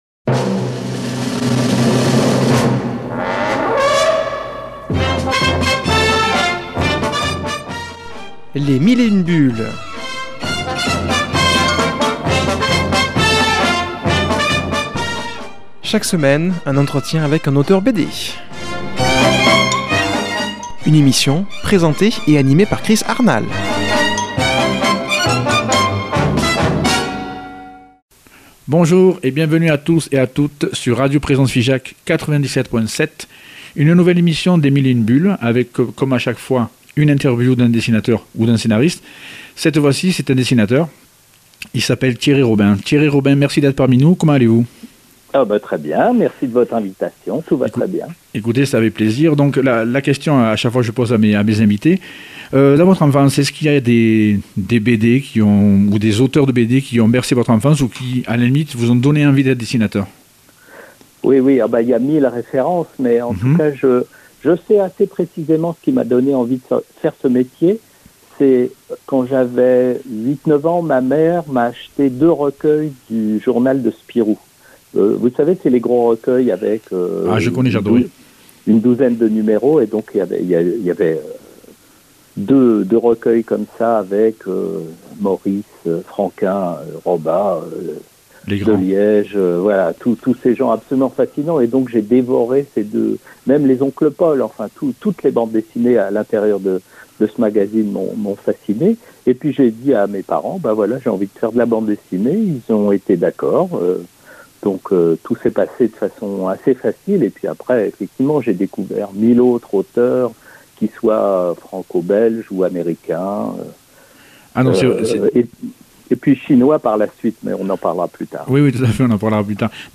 invitée au téléphone